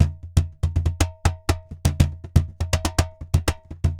Cajon_Baion 120_2.wav